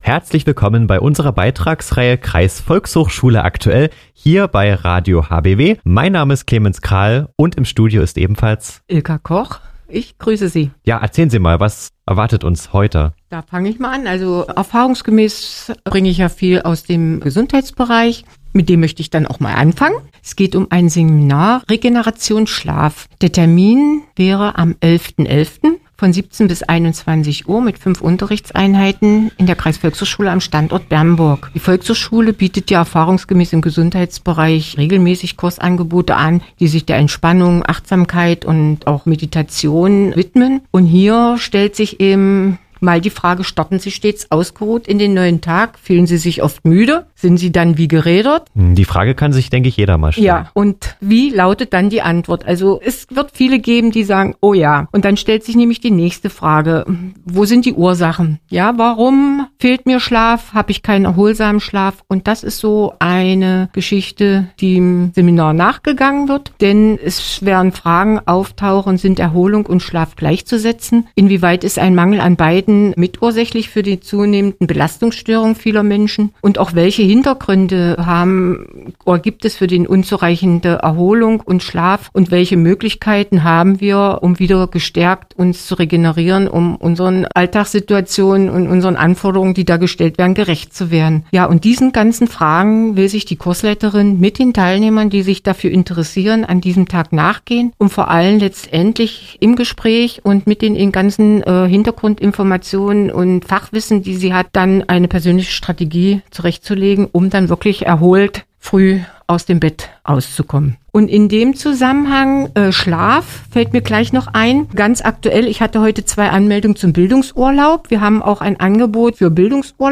Hörbeitrag vom 7. November 2024